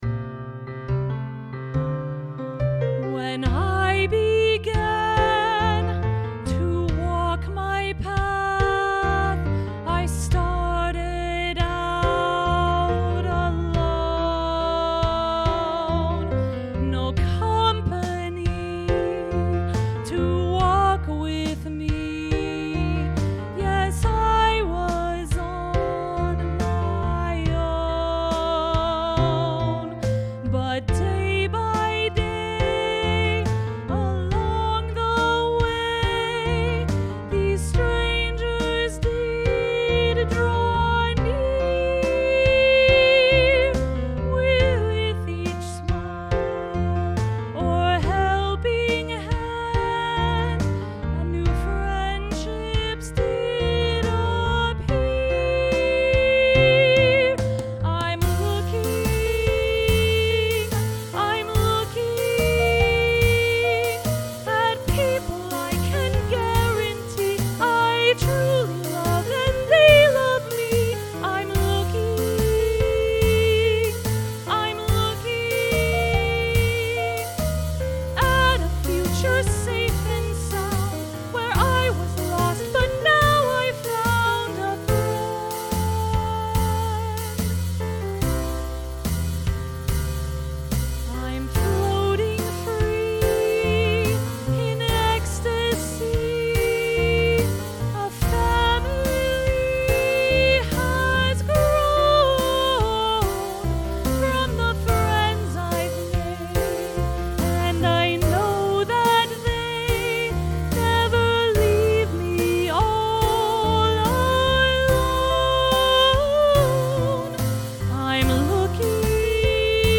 The primary vibe is the golden age of Broadway, but there’s some gospel, there’s jazz, there’s funk; there’s even a bit of boy-band/BTS snuck in here and there. Here are some rough demos of a few of the songs: